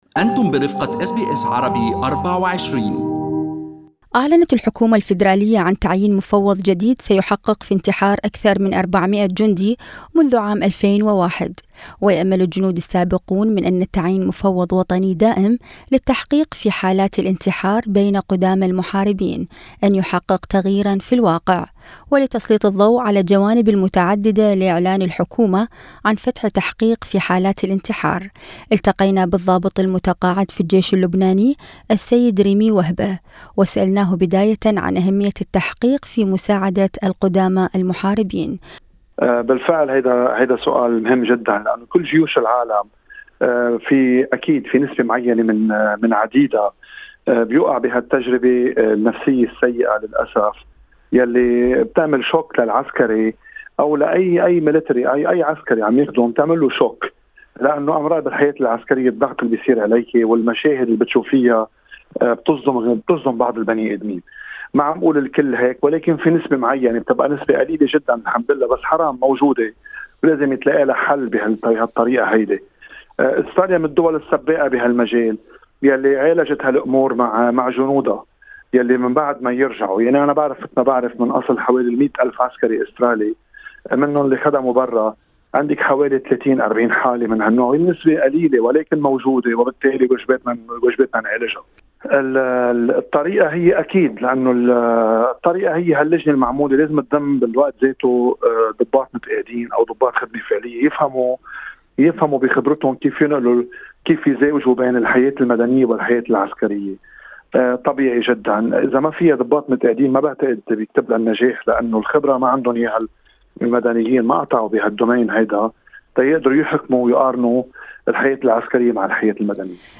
عسكريان سابقان يشرحان أسباب انتحار الجنود الأستراليين السابقين